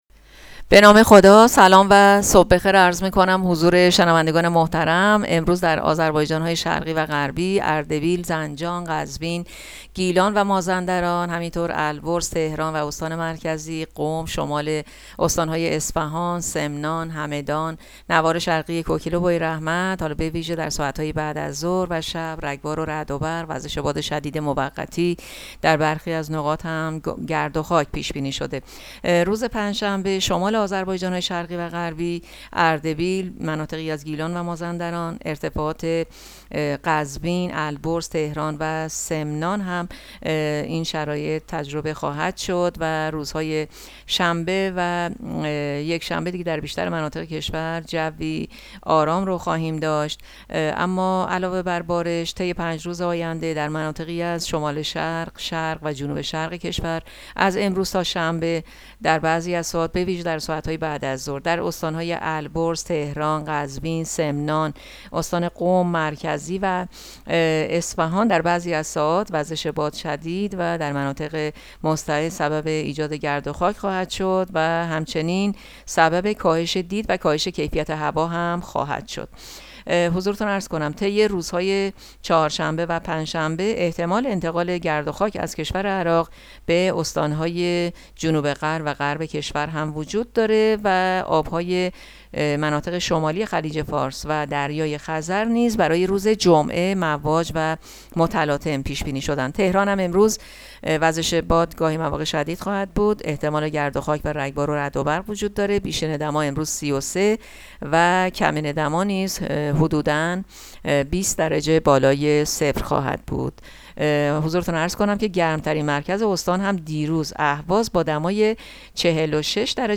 گزارش رادیو اینترنتی پایگاه‌ خبری از آخرین وضعیت آب‌وهوای ۷ خرداد؛